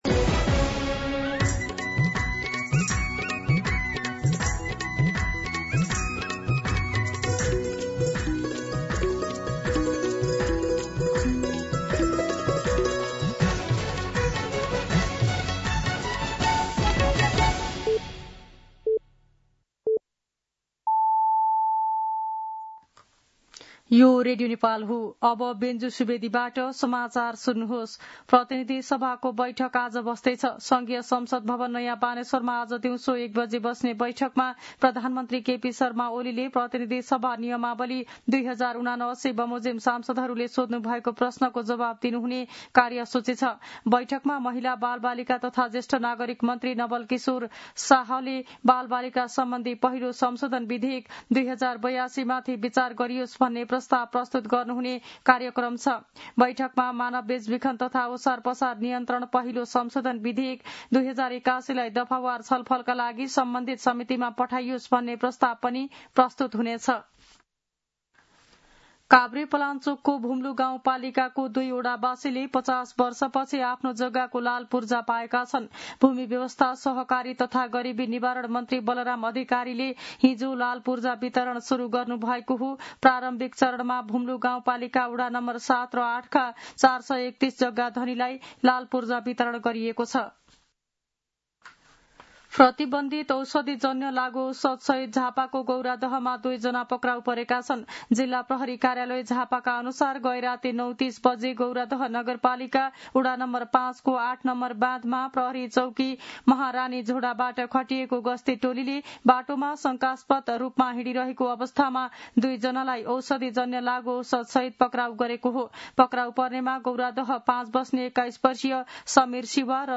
मध्यान्ह १२ बजेको नेपाली समाचार : ४ भदौ , २०८२